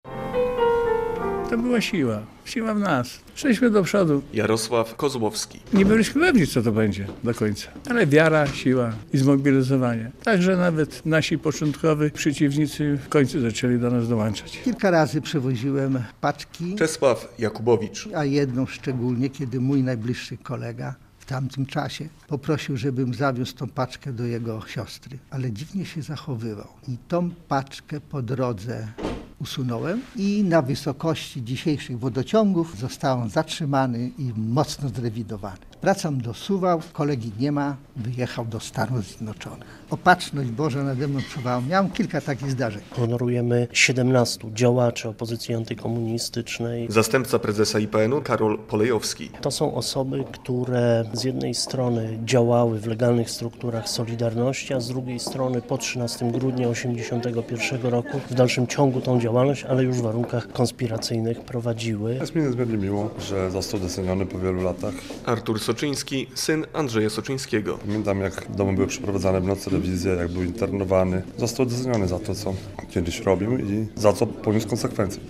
W Białymstoku wręczono Krzyże Wolności i Solidarności za działania na rzecz niepodległości Polski - relacja